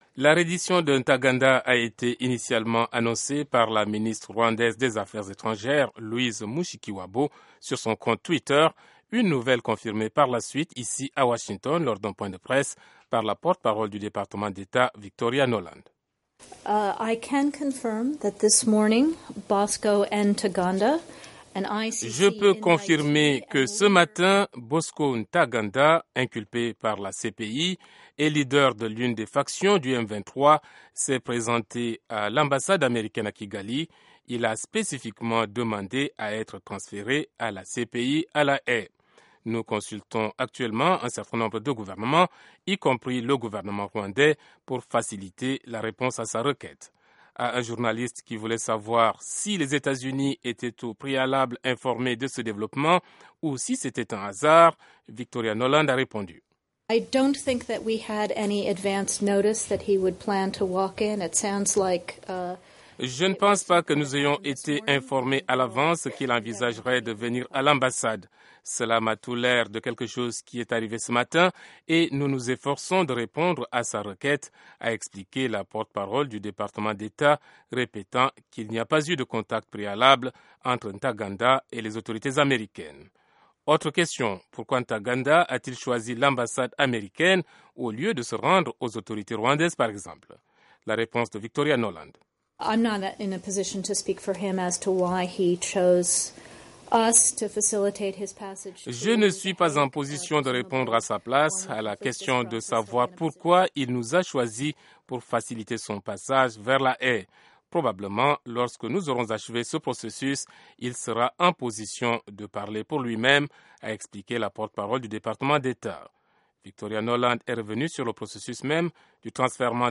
Lambert Mende, porte-parole du gouvernement congolais